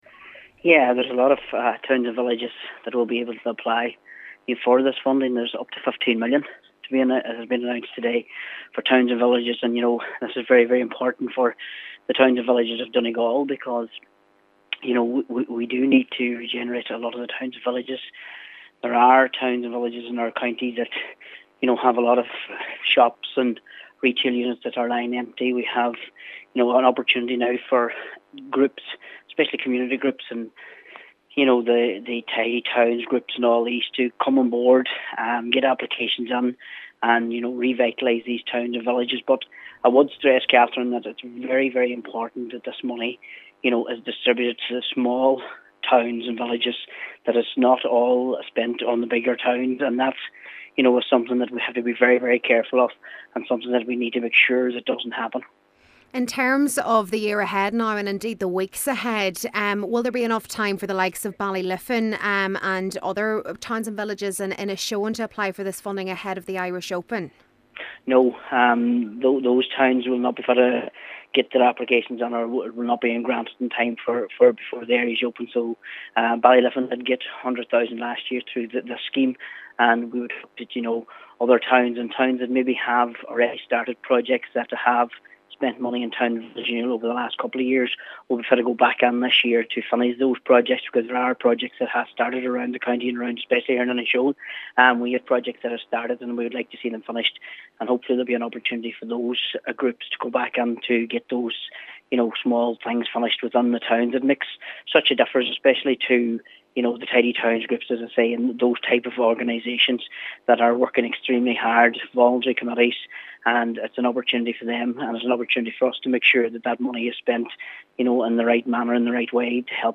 Cllr Martin McDermott says there are many smaller communities in Donegal which would be eligable and its important that they are condisered: